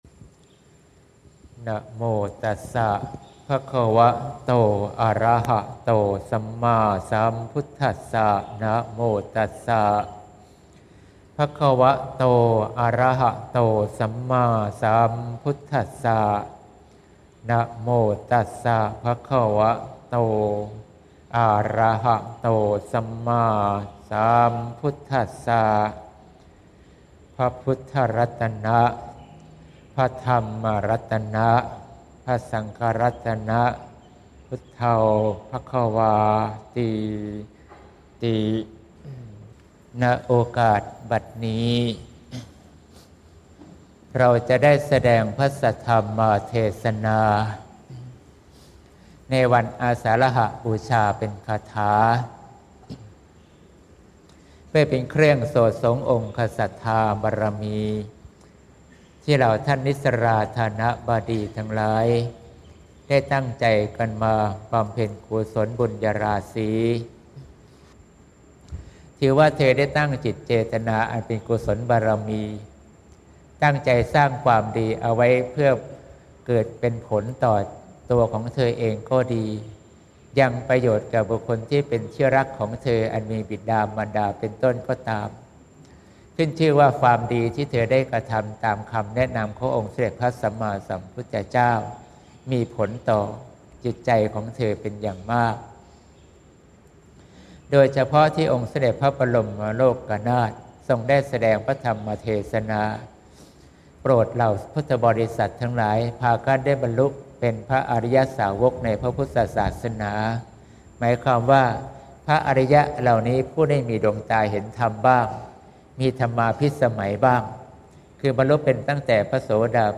เทศน์วันอาสาฬหบูชา (เสียงธรรม ๑๐ ก.ค. ๖๘)